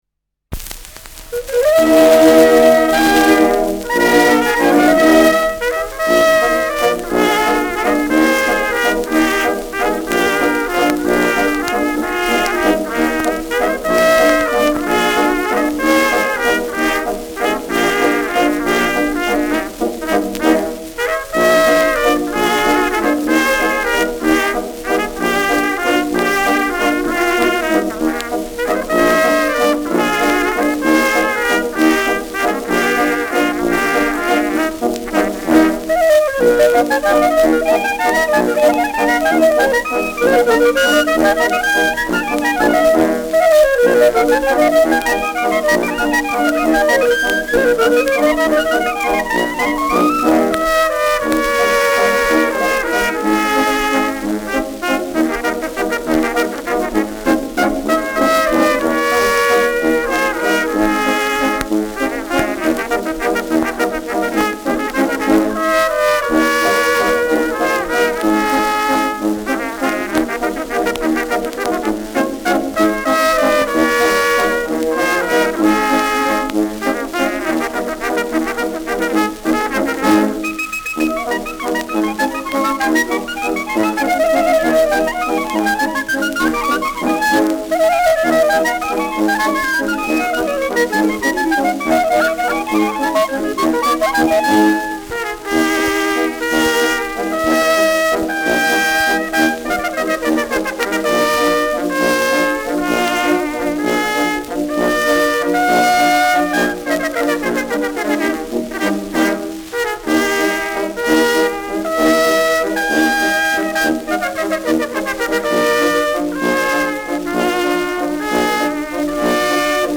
Schellackplatte
präsentes Knistern : leiert : abgespielt : leichtes Rauschen : gelegentliches Knacken